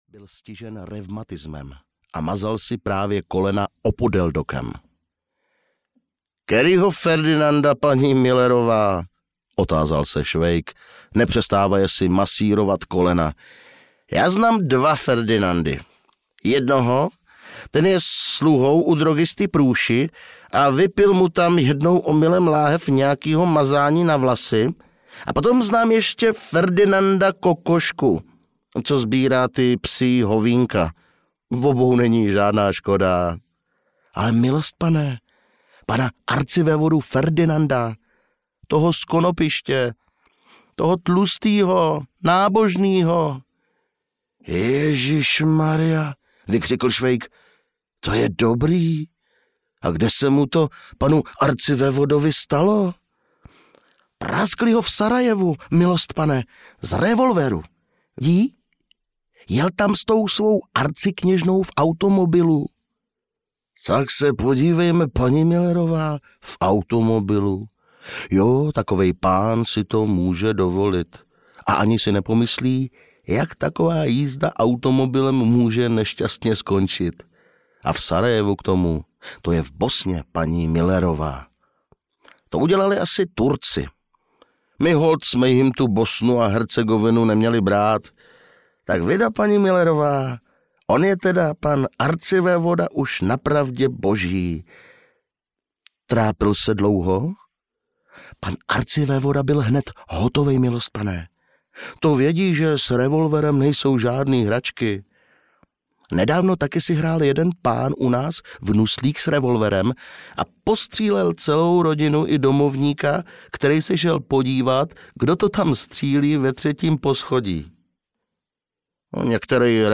Ukázka z knihy
osudy-dobreho-vojaka-svejka-v-zazemi-1-dil-audiokniha